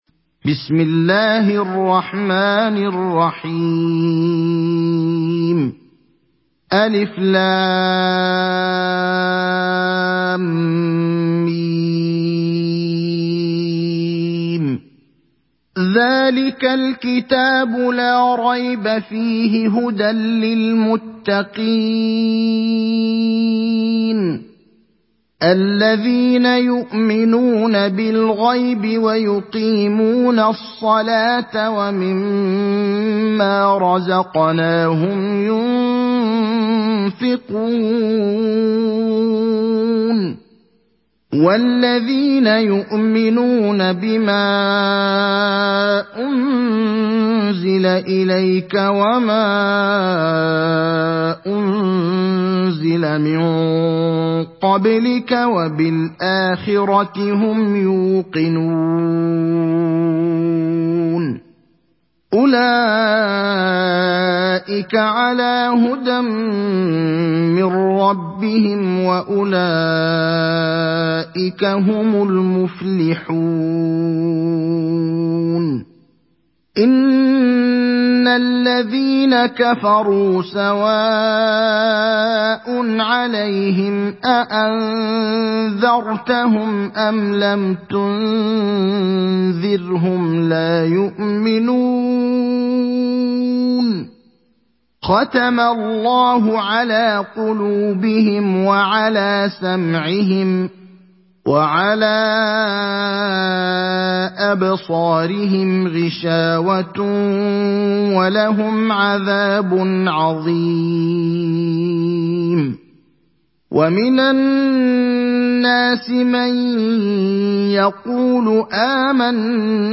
Surah Al Baqarah mp3 Download Ibrahim Al Akhdar (Riwayat Hafs)
Surah Al Baqarah Download mp3 Ibrahim Al Akhdar Riwayat Hafs from Asim, Download Quran and listen mp3 full direct links